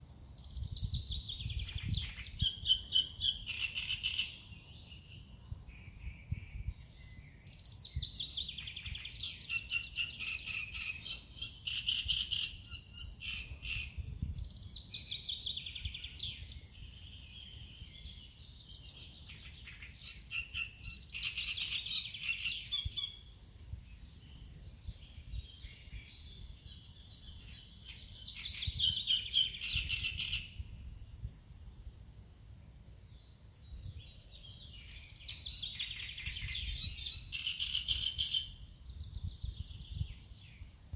Great Reed Warbler, Acrocephalus arundinaceus
Administratīvā teritorijaRīga
StatusSinging male in breeding season